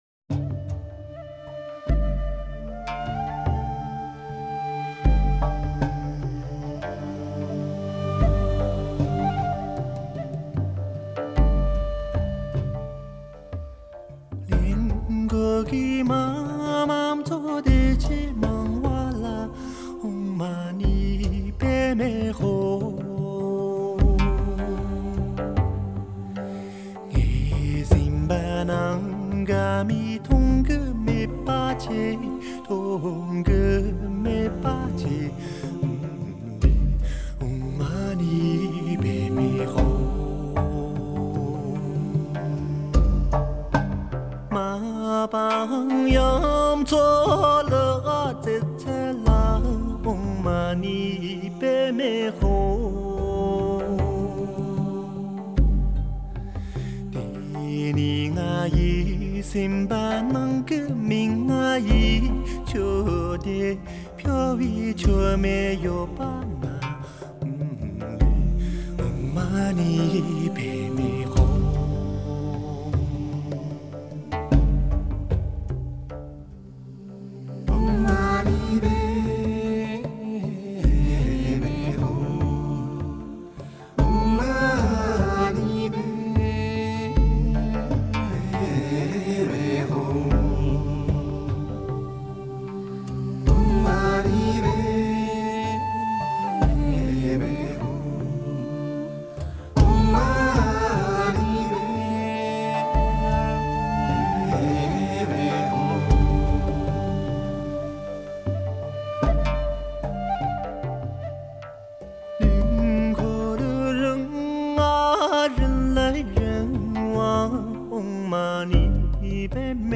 靈魂深處的天籟詠嘆
單簧管、笛、打擊樂及大樂隊的色彩調染，創造出異域色彩濃烈、現代情感深厚的多情氛圍。